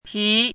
chinese-voice - 汉字语音库
pi2.mp3